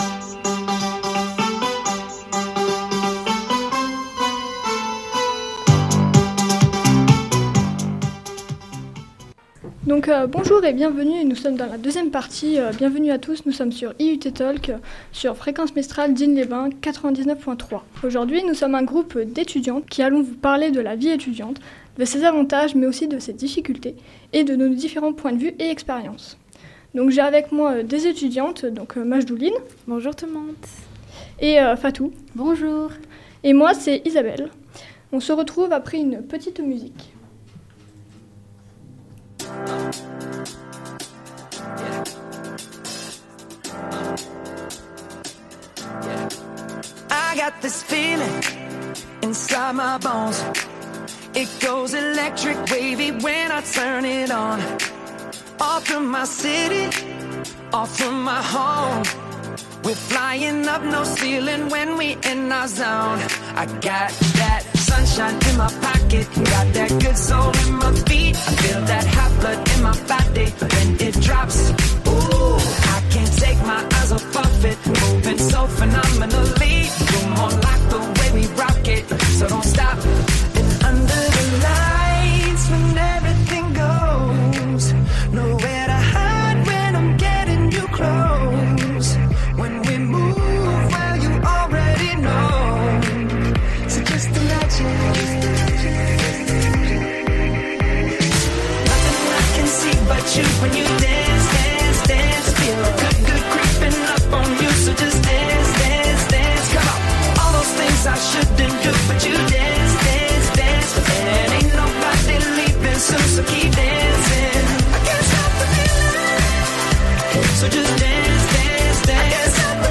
Une émission réalisée entièrement par des étudiantes, et étudiants de l'IUT d'Aix-Marseille Site de Digne-les-Bains saison 2023-2024, en 2ème année d'agronomie.